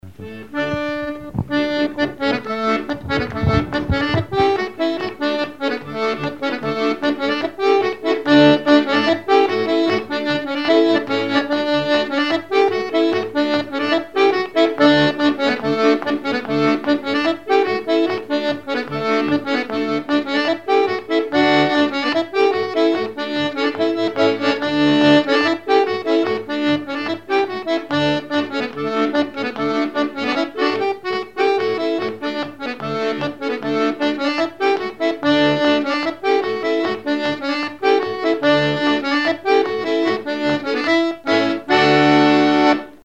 Chants brefs - A danser
instrumentaux à l'accordéon diatonique
Pièce musicale inédite